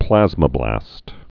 (plăzmə-blăst)